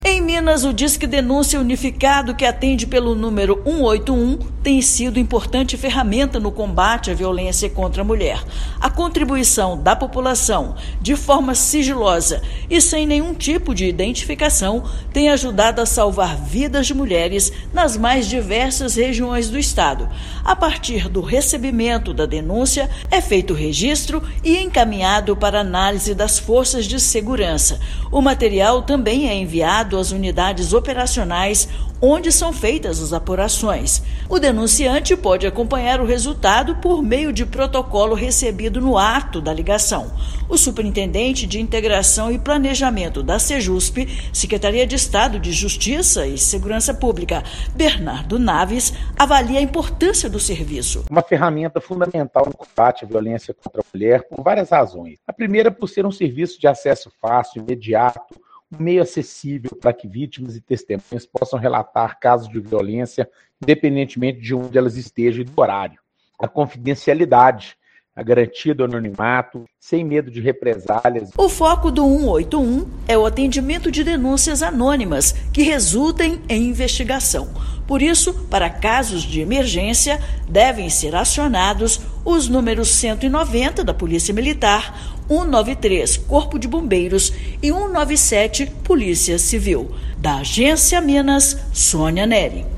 [RÁDIO] Disque Denúncia é importante aliado no combate à violência contra a mulher em Minas
Informações podem ser repassadas ao serviço de forma totalmente sigilosa, sem nenhum tipo de identificação. Ouça matéria de rádio.